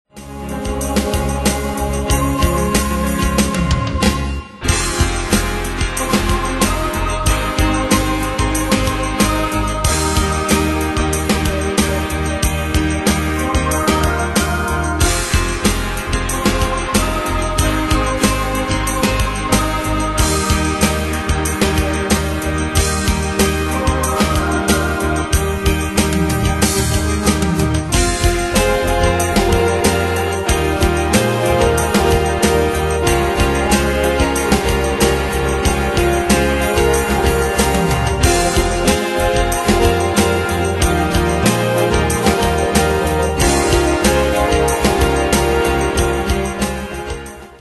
Danse/Dance: BalladeRock Cat Id.
Pro Backing Tracks